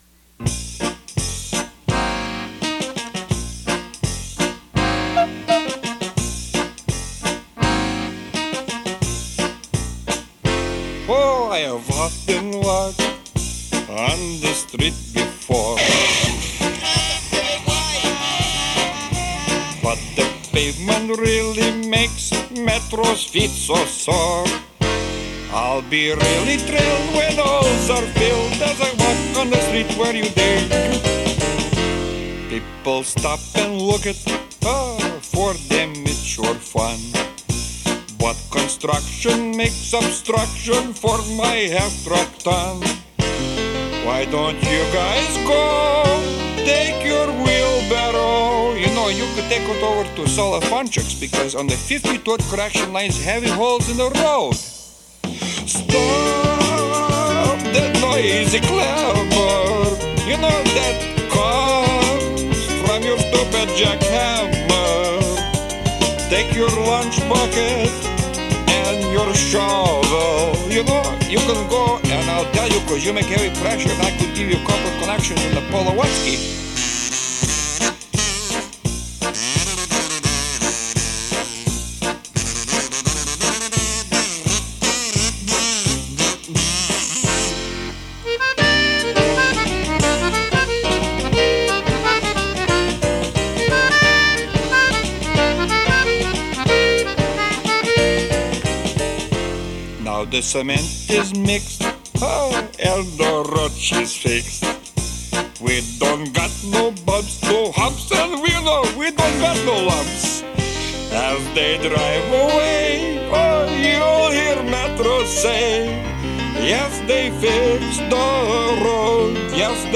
The sound quality of this 8-track is surprisingly fantastic!